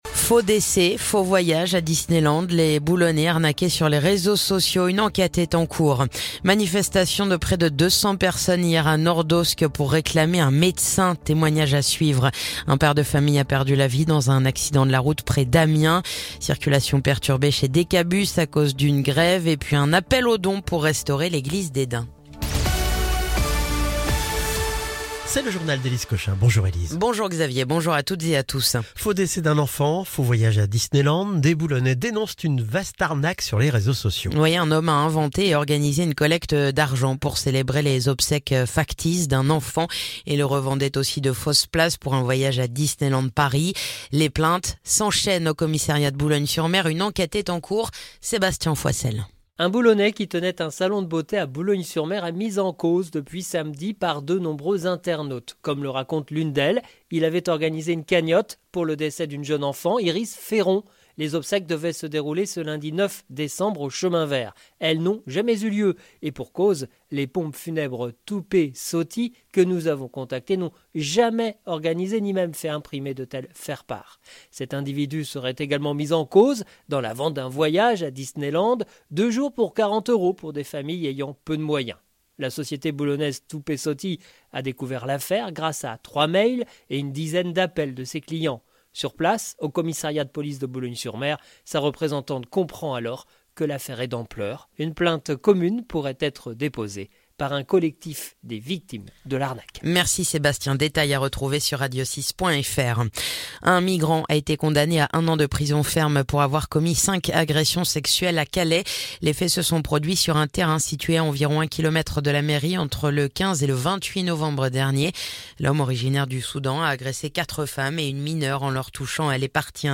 Le journal du mardi 10 décembre